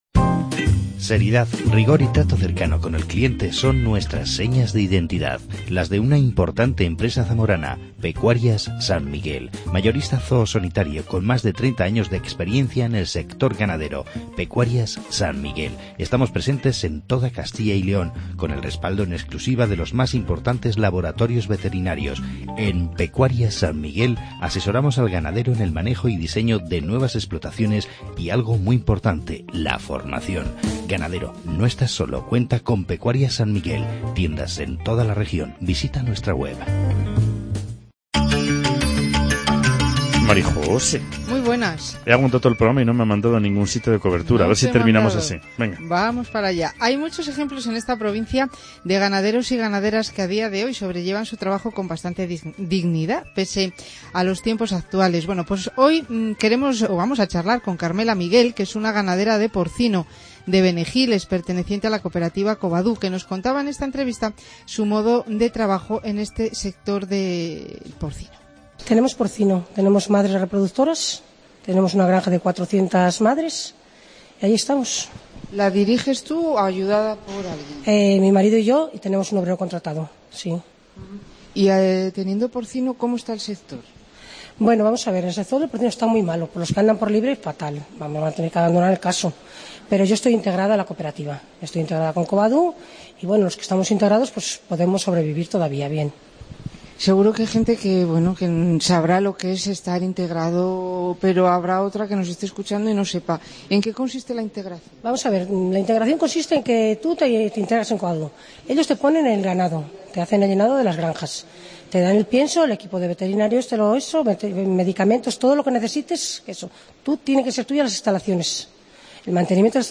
Charla sobre el sector porcino con la ganadera